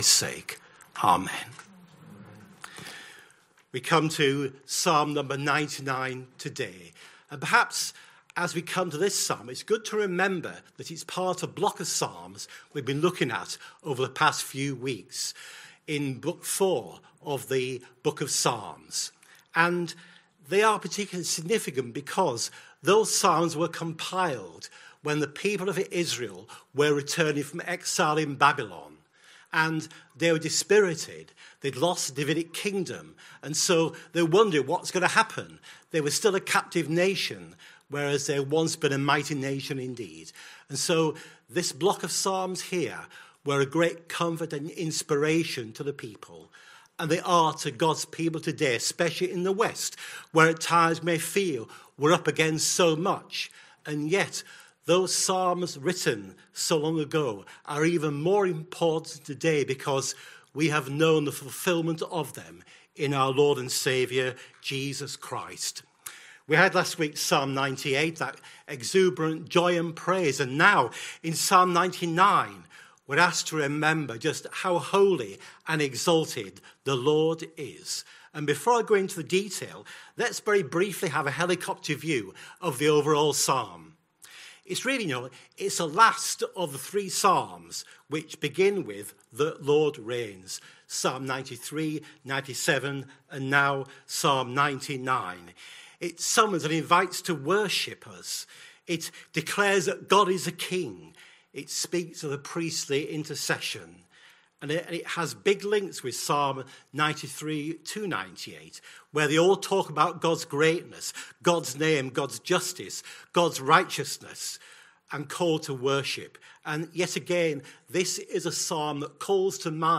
Sunday PM Service Sunday 12th October 2025 Speaker